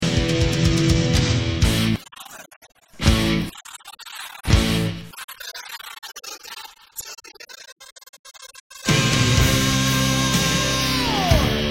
Surround Remix Sample
Clear,surround sound ,